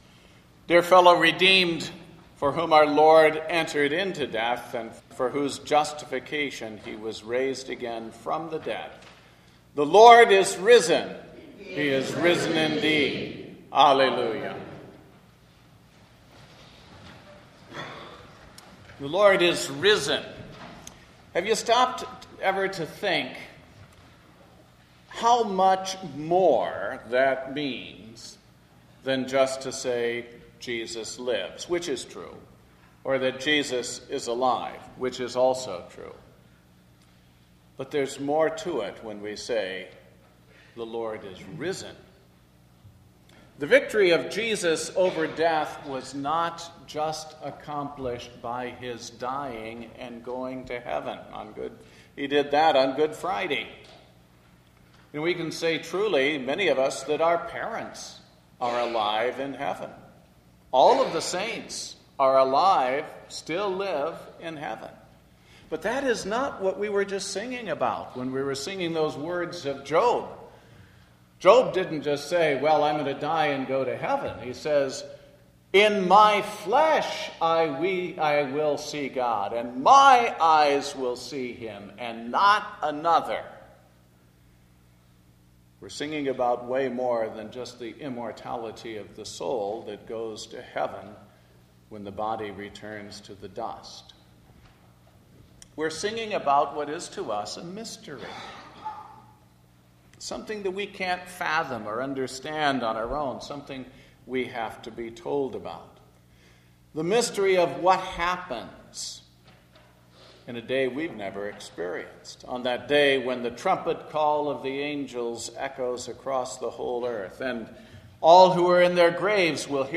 Sermon based on Mark 8:27-38 Second Sunday in Lent A RIGHT PERSPECTIVE ON THE CHRISTIAN GOSPEL.